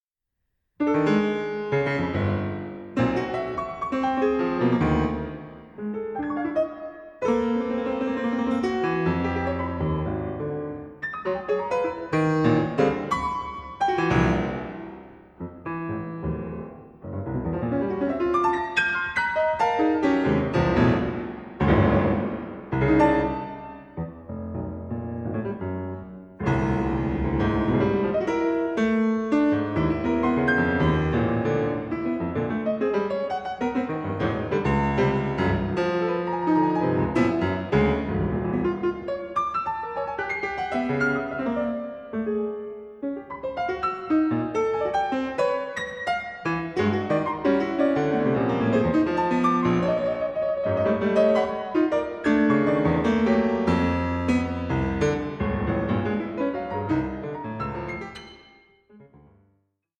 44.1/16 Stereo  10,99 Select